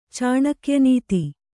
cāṇakya nīti